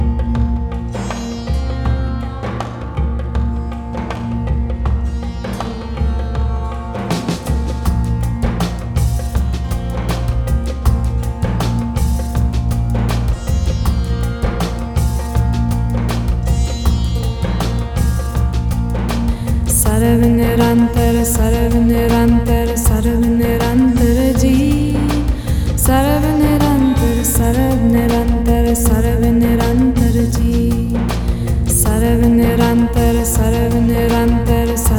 # Meditation